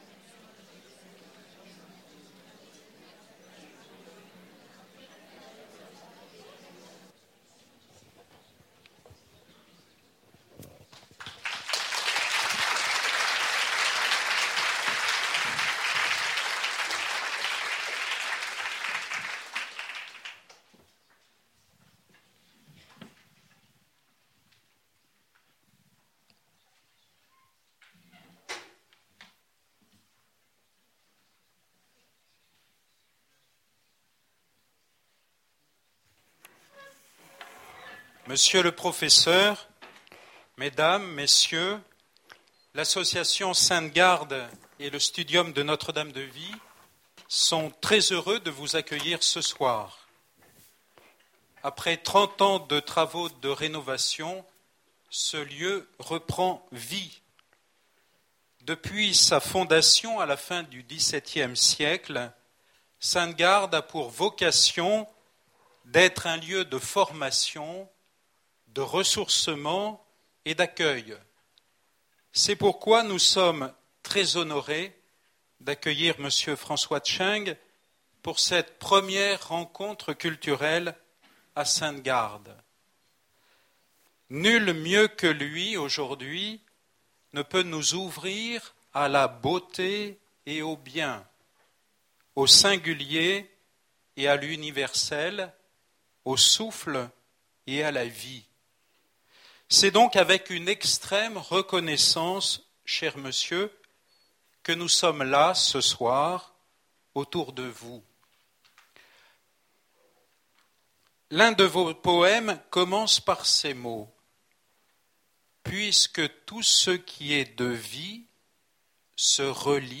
Cette rencontre a eu lieu le jeudi 25 septembre 2014 à Notre-Dame de Sainte Garde 84210 Saint-Didier
Conférences - CHENG François poète, romancier, de l’Académie Française.